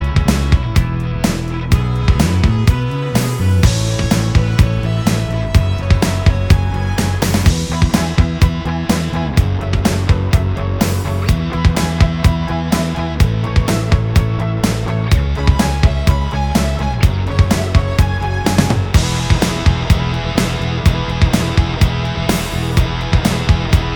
Minus All Guitars Pop (1980s) 3:44 Buy £1.50